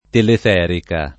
teleferica [ telef $ rika ] s. f.